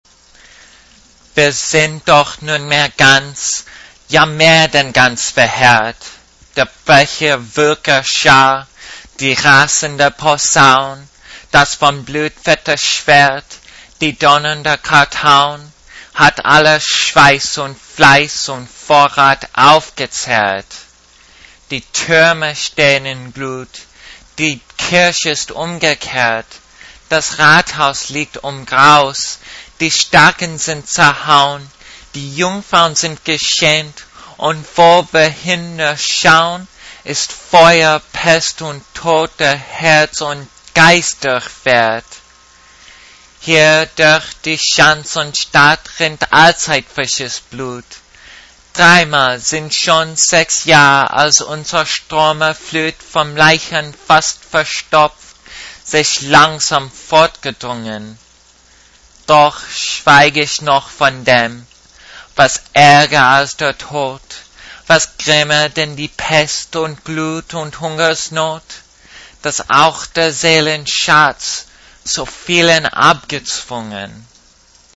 Non-native speaker
Accent: Non-native American, but difficult to distinguish from native.